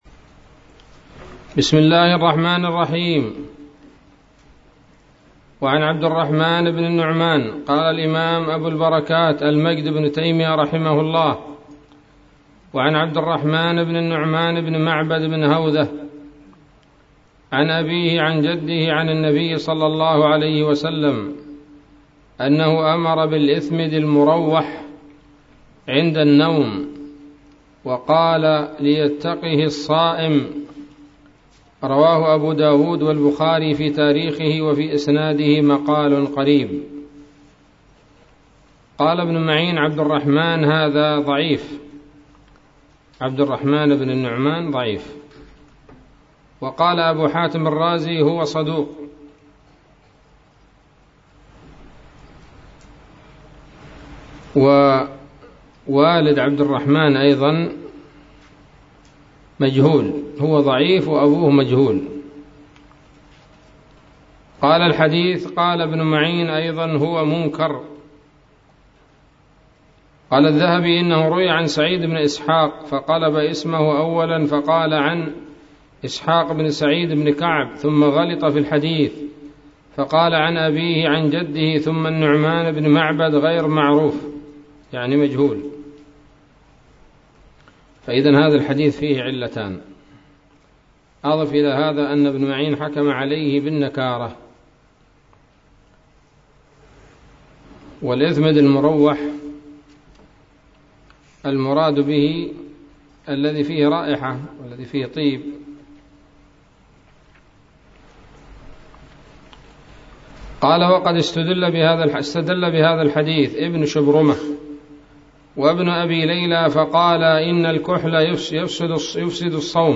الدرس الثاني عشر من كتاب الصيام من نيل الأوطار